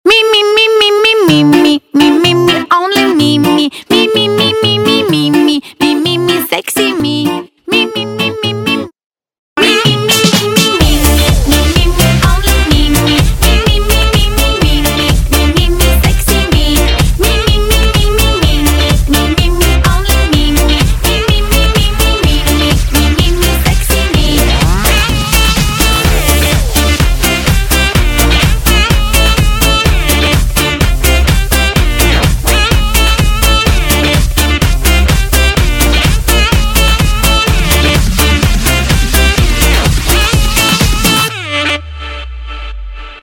• Качество: 192, Stereo
поп
женский вокал
забавные
веселые
заводные